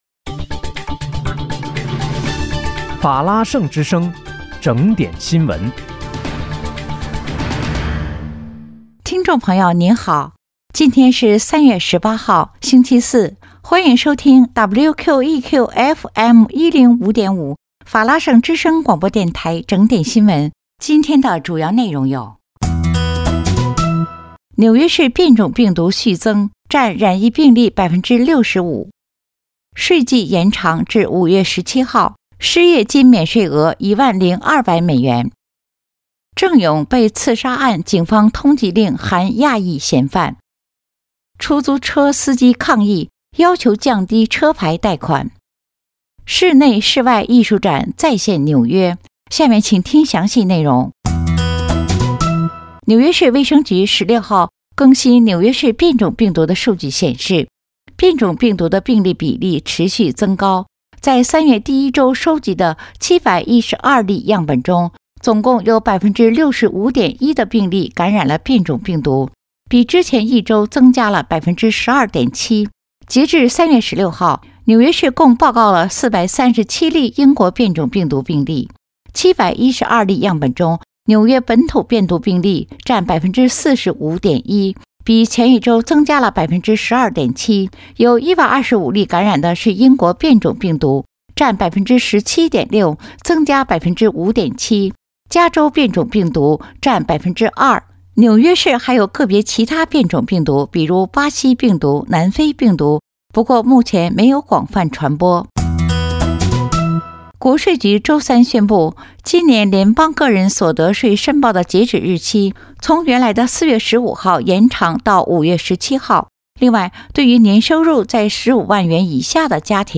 3月18日（星期四）纽约整点新闻
听众朋友您好！今天是3月18号，星期四，欢迎收听WQEQFM105.5法拉盛之声广播电台整点新闻。